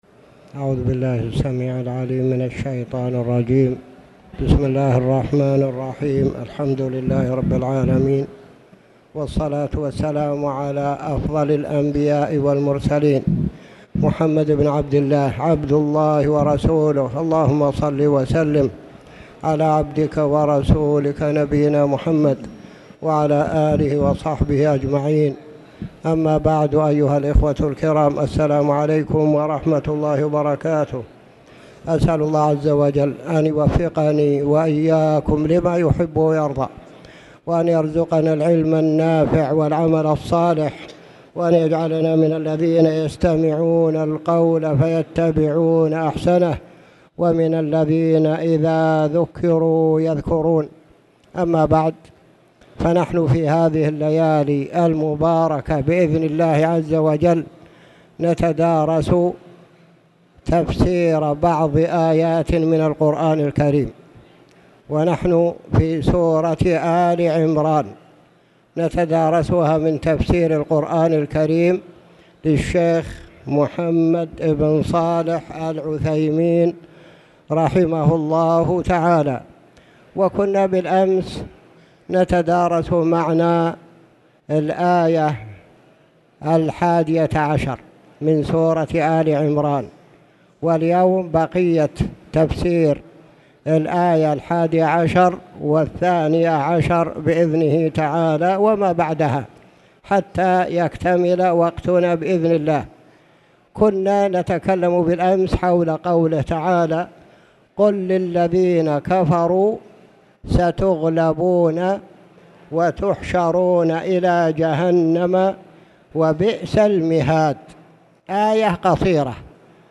تاريخ النشر ١٢ ربيع الثاني ١٤٣٨ هـ المكان: المسجد الحرام الشيخ